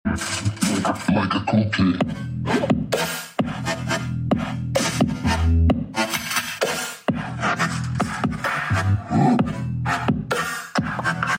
BASS TEST EXTREME MEMBRAN MOVMENT